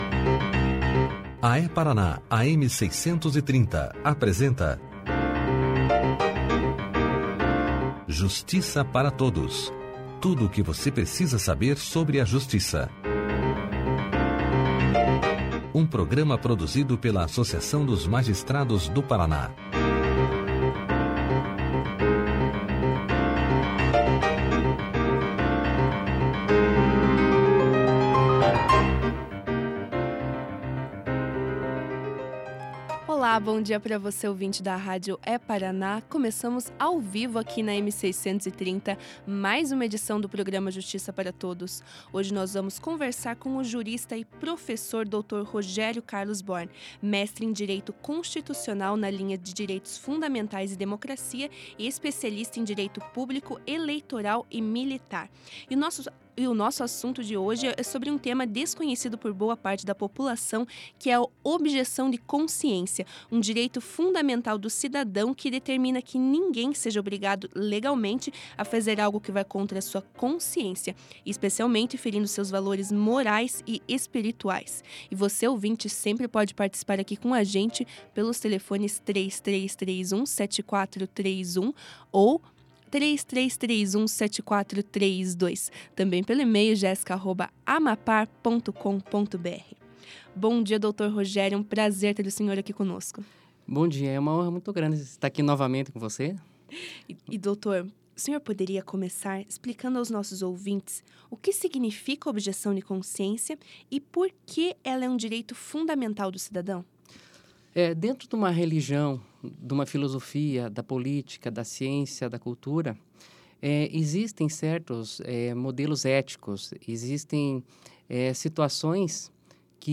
Clique aqui e ouça a entrevista do jurista e professor